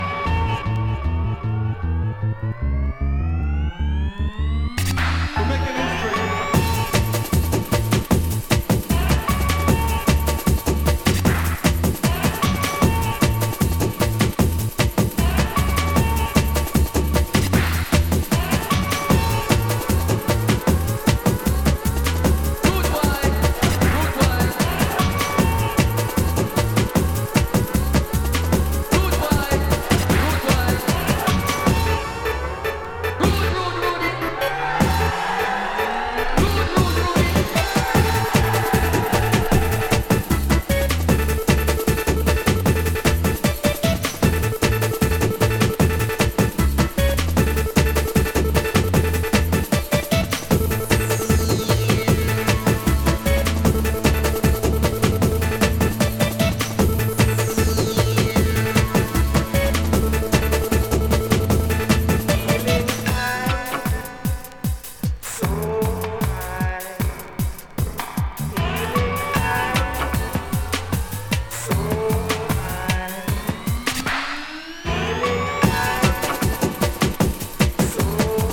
ハッピーなアレンジのBreakbeat〜Hardcore